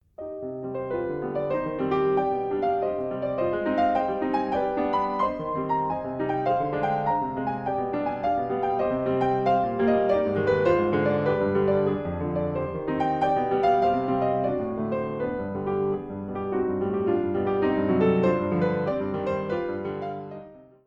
Étude de main gauche pour les basses faites à contretemps.